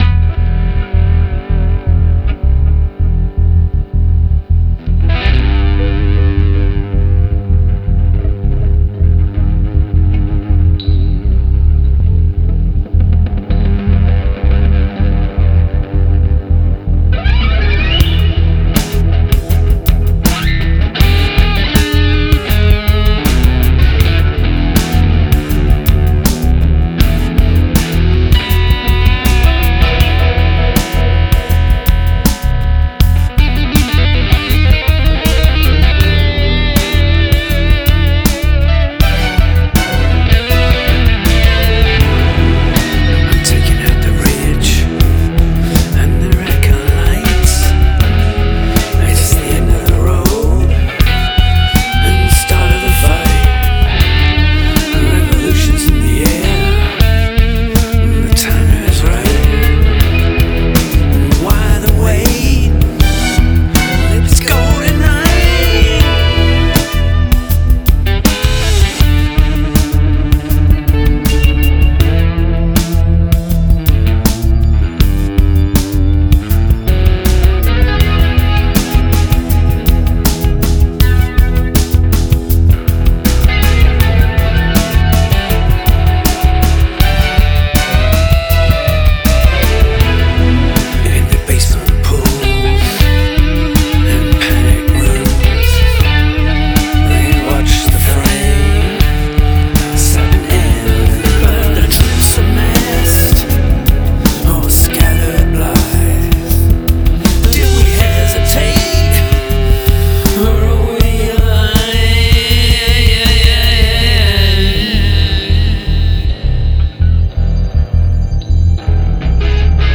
Lead electrical guitar, Strings
Electric guitars, rhythm track, vocals